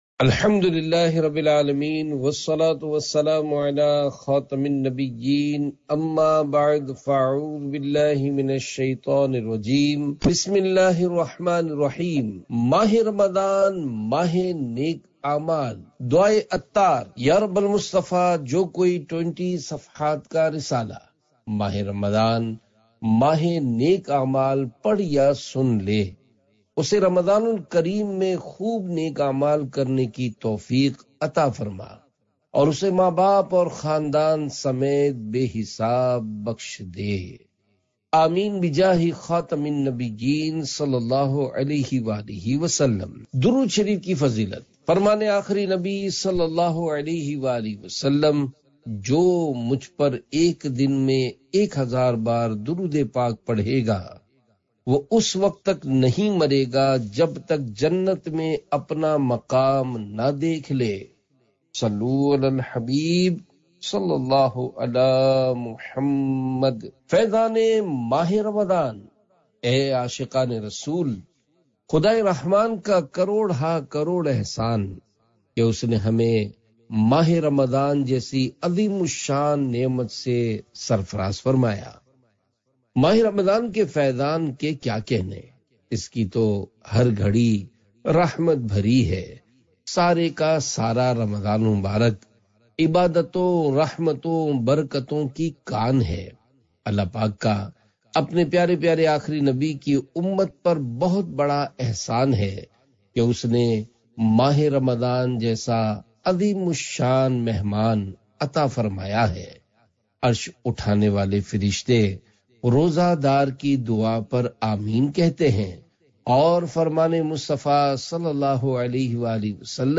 Audiobook - Mah e Ramzan Mah e Naik Aamal